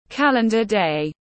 Ngày dương lịch tiếng anh gọi là calendar day, phiên âm tiếng anh đọc là /ˈkæl.ən.dər deɪ/
Calendar day /ˈkæl.ən.dər deɪ/